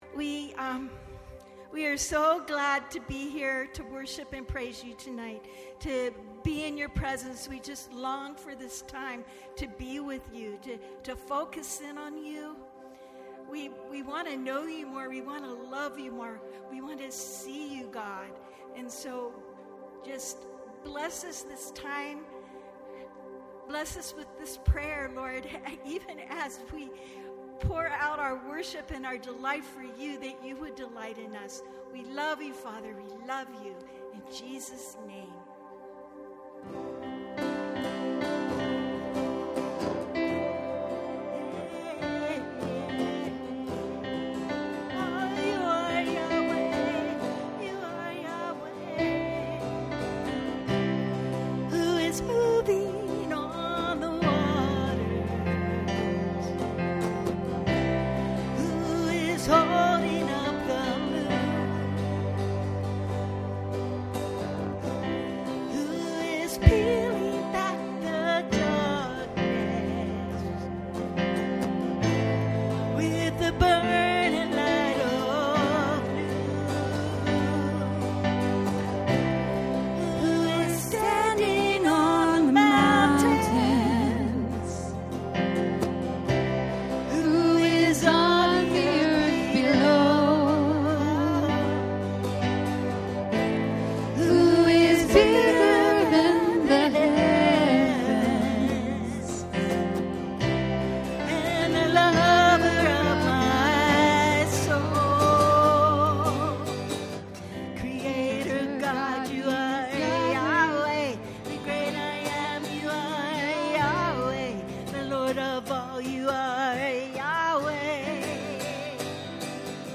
Latest was 8/3/25 – Sunday Service.